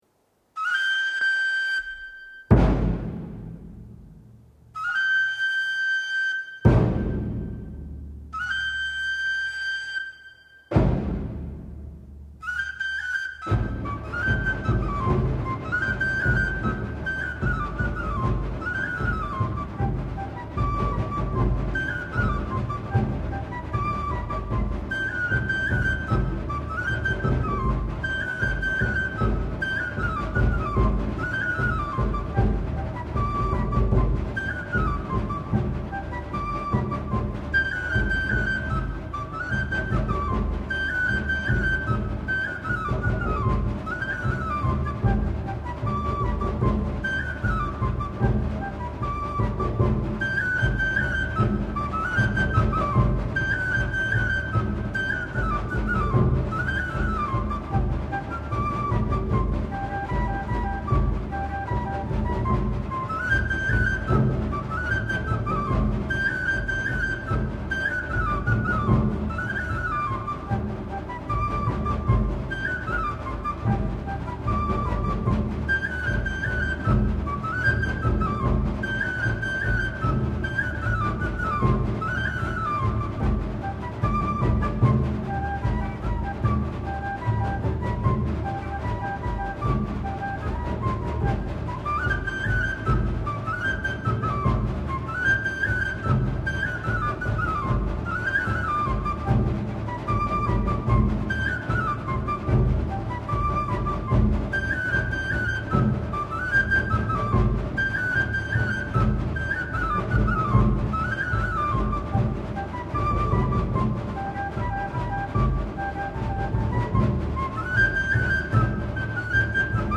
Local: - Alentejo